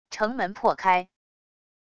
城门破开wav音频